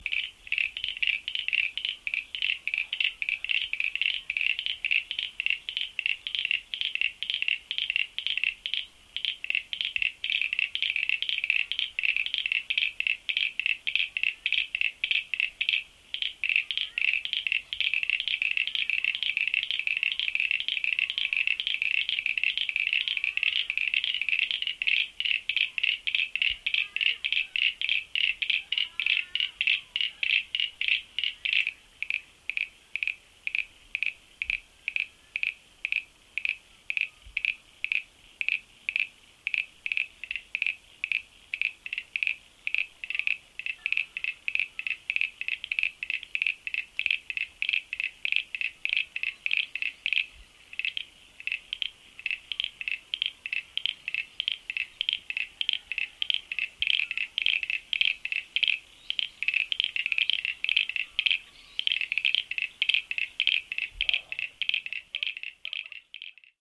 Two (or perhaps more) in particular are battling it out for supremacy in our garden pond, as can be heard in the audio recording below.
Anyway, take a minute to listen to the hypnotic clickety-click calls by clicking on the audio below.
common-froglet-call.mp3